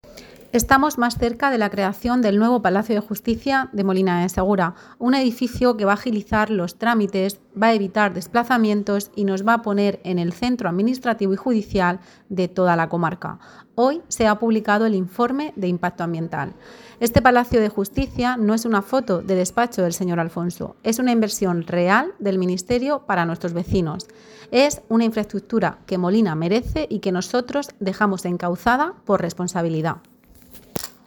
• Declaraciones de Isabel Gadea
La portavoz del Grupo Municipal Socialista, Isabel Gadea, ha destacado la importancia de este hito: